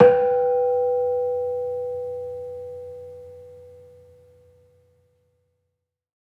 Gamelan Sound Bank
Kenong-resonant-B3-f.wav